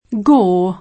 Gower [ingl.
g0o secondo i casi; italianizz. g0ver] cogn. — es.: Leveson-Gower [l2uS-n g0o], casato nobile; invece Gower Street [g# Str&it], strada di Londra (così comunemente chiamata oggi, sebbene derivi il suo nome da lady Gertrude Leveson-Gower, 1715-94)